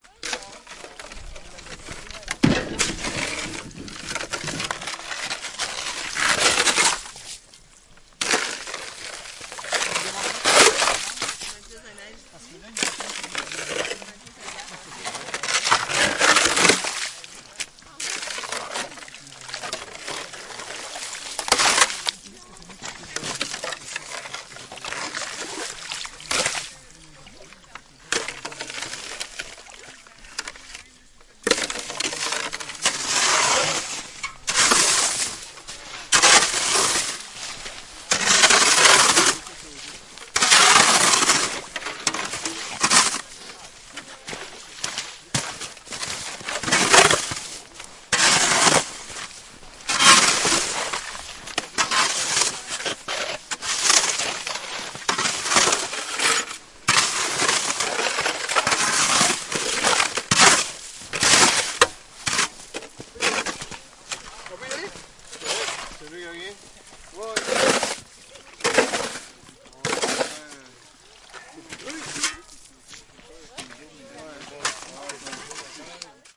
随机 " 潜水员团体从水中铲冰 加拿大渥太华
描述：小组冰潜水员铲起从水渥太华，Canada.flac的冰
Tag: 潜水员